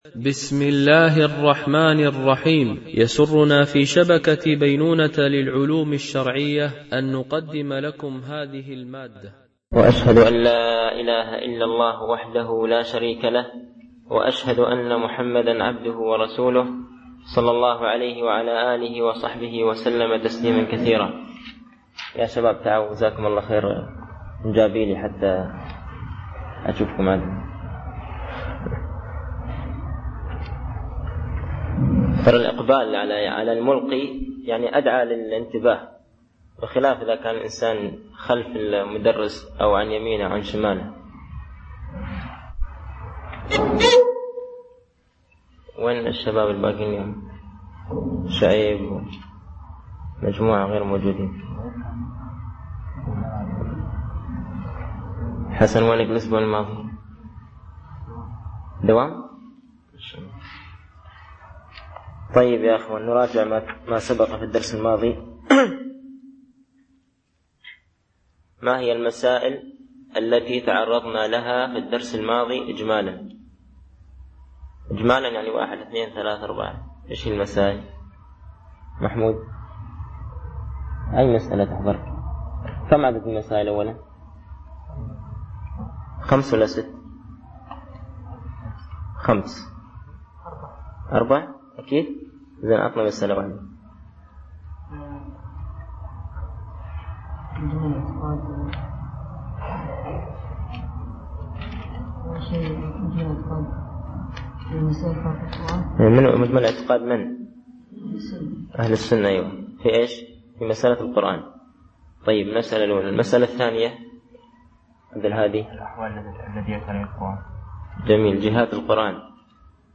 شرح أعلام السنة المنشورة ـ الدرس 17 هل صفة الكلام ذاتية أو فعلية ؟ (1)